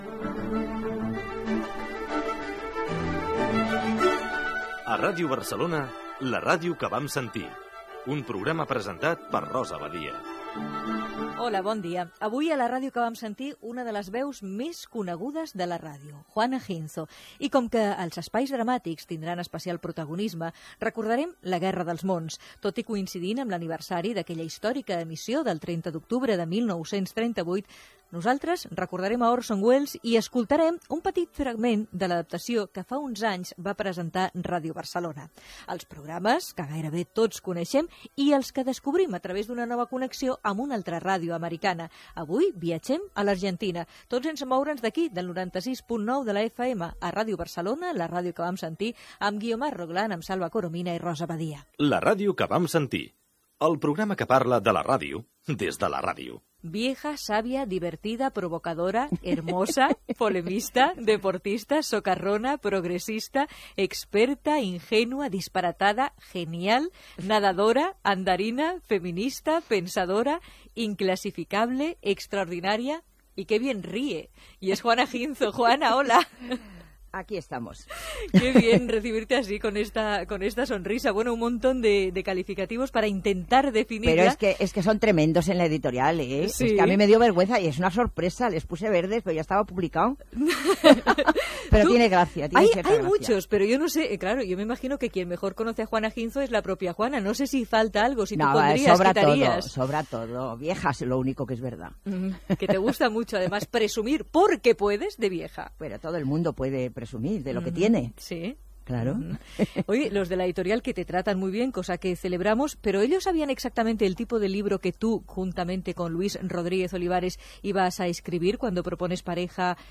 Lectura d'un poema de Quevedo.